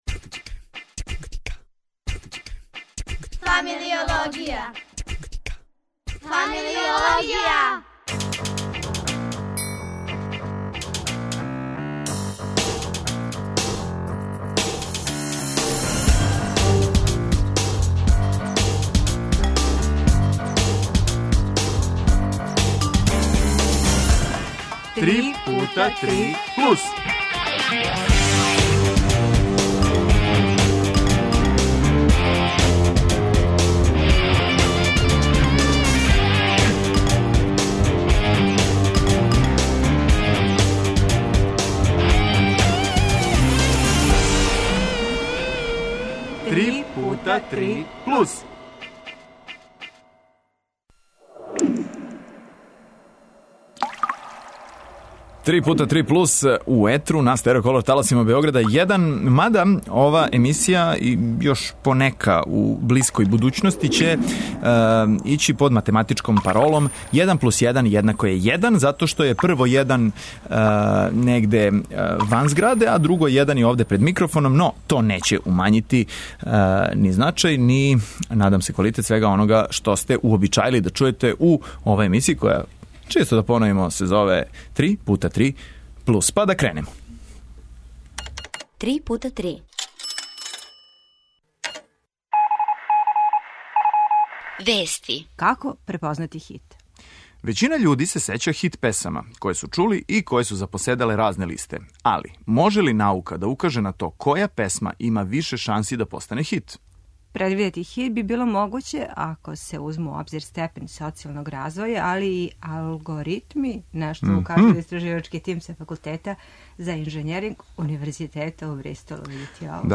гитаристи.